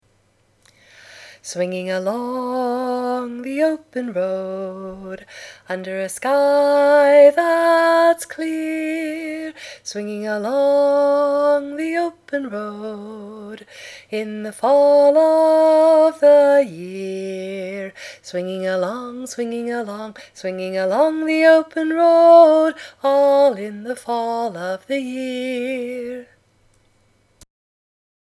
a great part-song
It’s a perfect hiking song